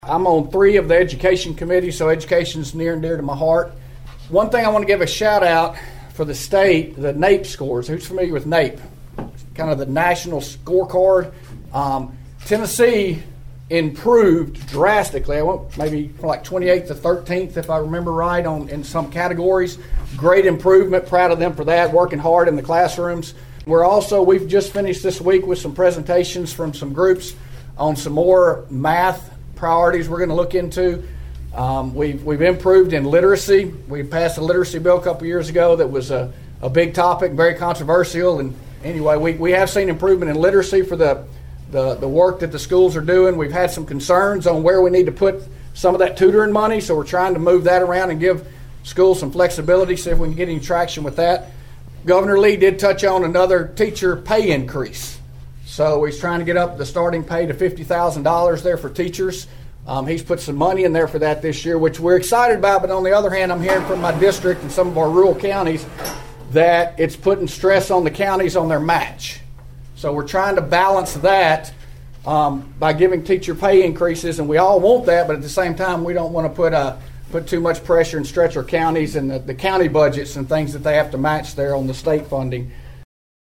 The lawmakers took part in the annual Obion County Farm Bureau Legislative Coffee.
Representative Hurt, whose district contains a portion of Obion County, addressed those in attendance about current education issues.(AUDIO)